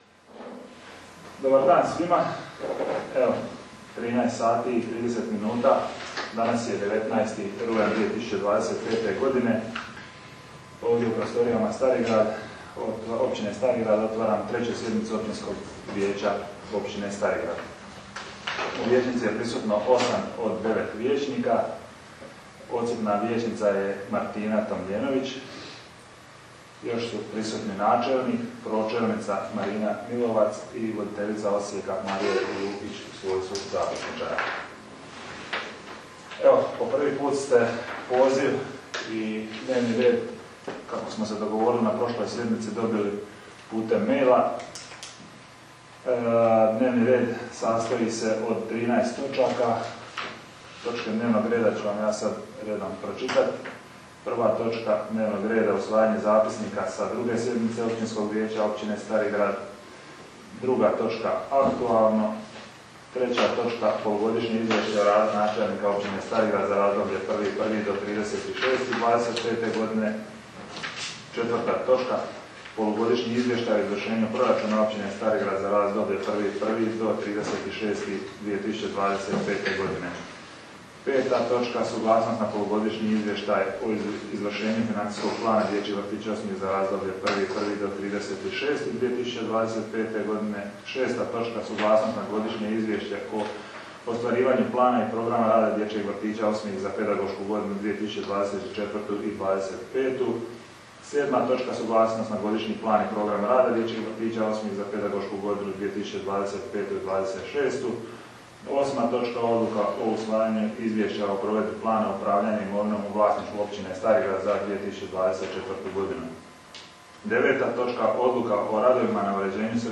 tonski zapis sa 21. sjednice Općinskog vijeća (1. i 2. dio):